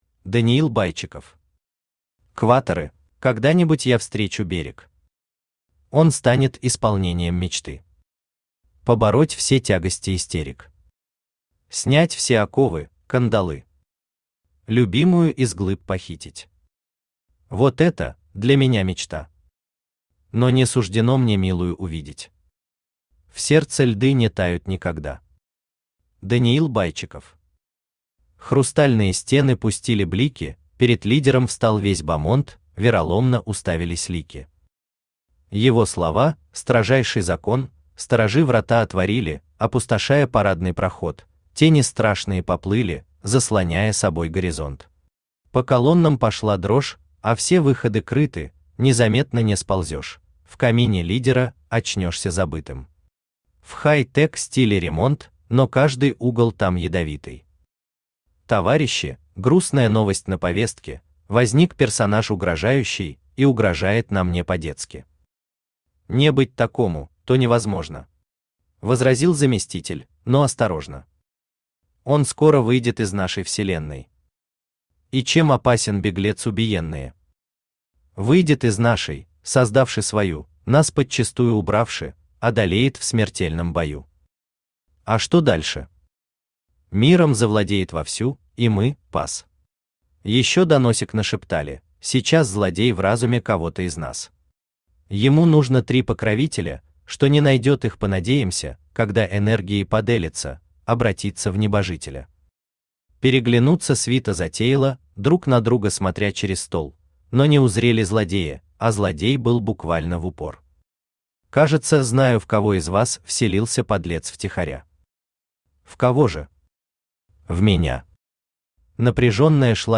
Аудиокнига Кваторы | Библиотека аудиокниг
Aудиокнига Кваторы Автор Даниил Владимирович Байчиков Читает аудиокнигу Авточтец ЛитРес.